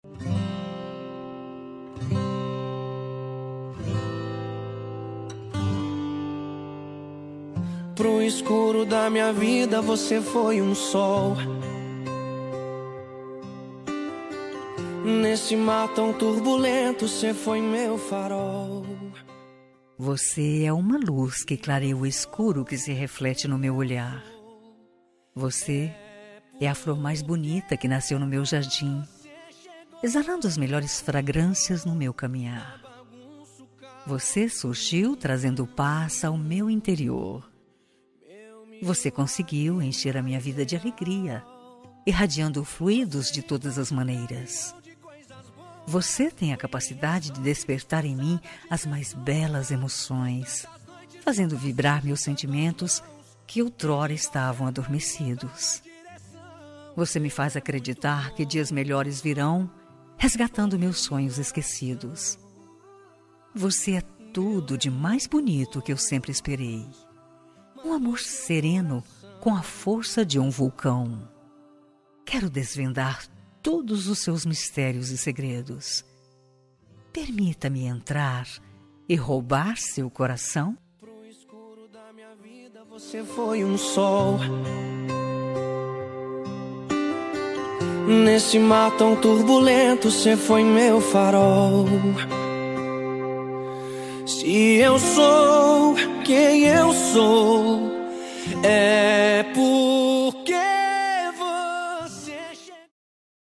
Telemensagem Romântica – Voz Feminina – Cód: 6456